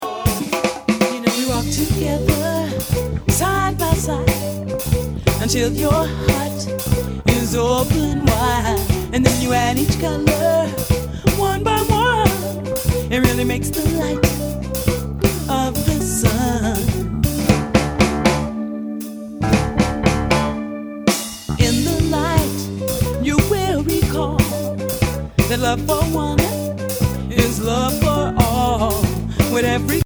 New Orleans Rhythm and Blues